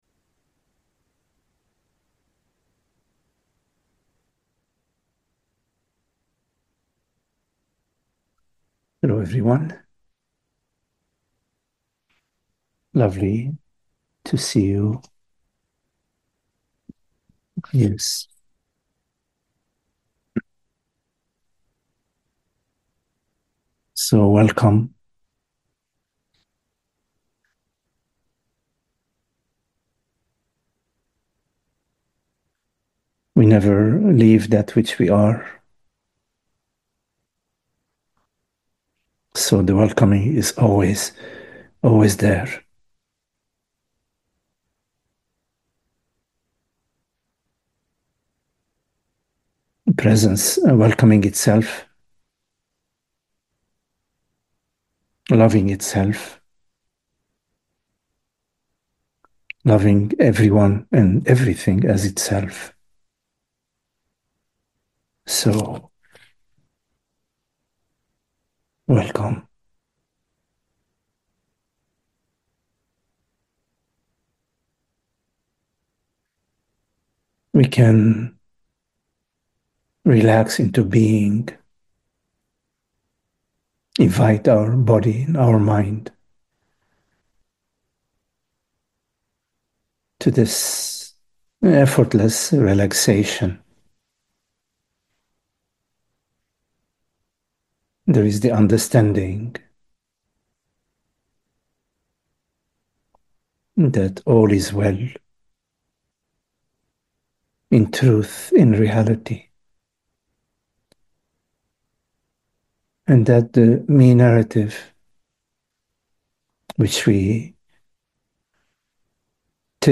Meditative contemplation